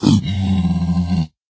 zpigangry3.ogg